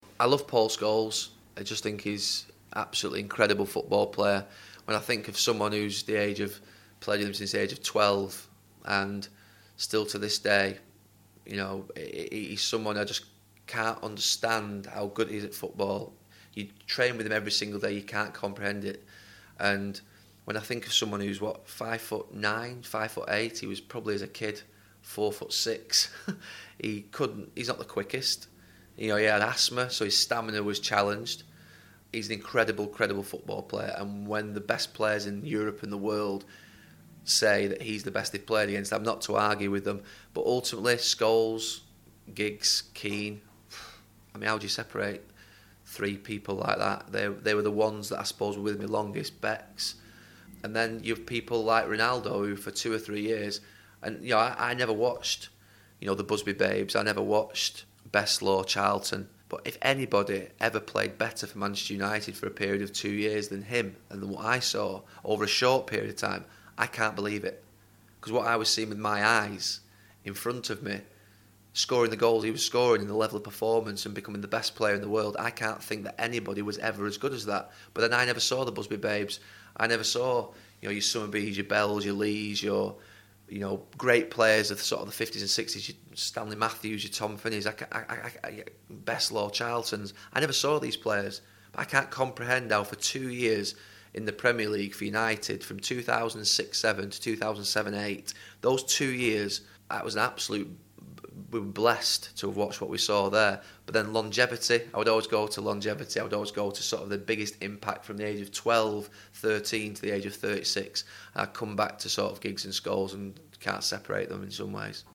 Hear it on BBC Radio Manchester from 6pm on the 29/11/2012.